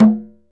Index of /90_sSampleCDs/Roland - Rhythm Section/PRC_Latin 1/PRC_Conga+Bongo